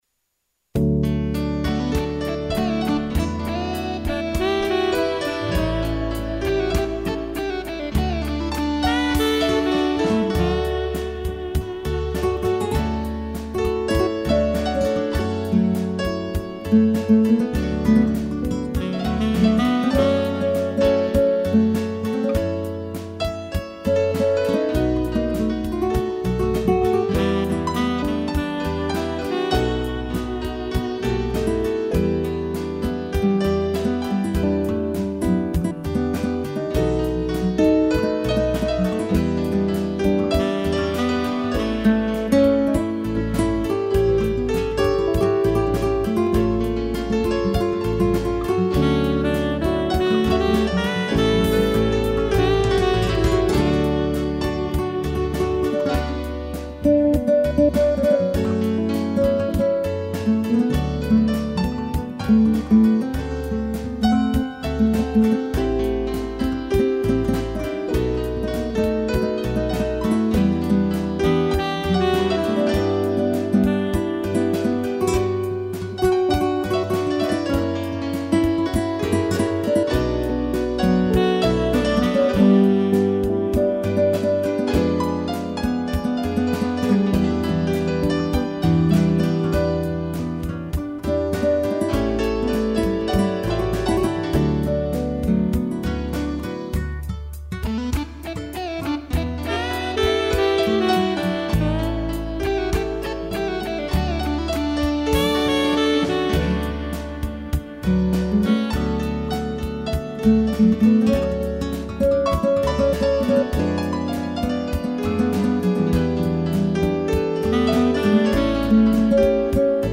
violão
piano e sax
instrumental